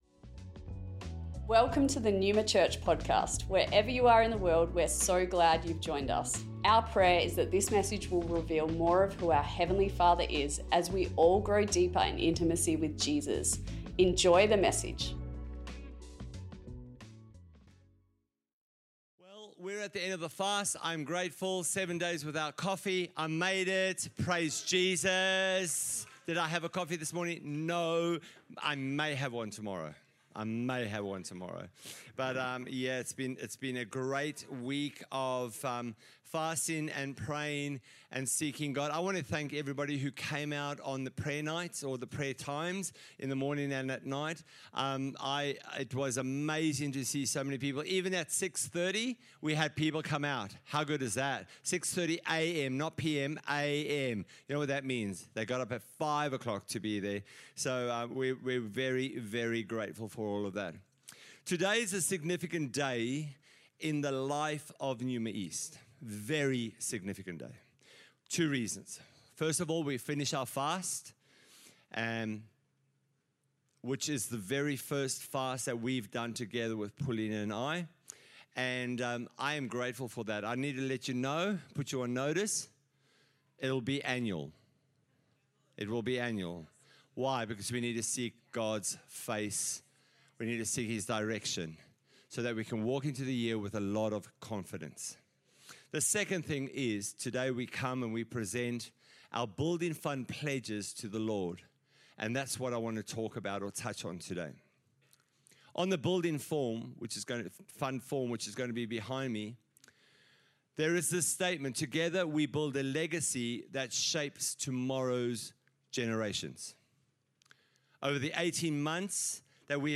Neuma Church Melbourne East Originally recorded on Sunday 23rd of February 2025 | 9AM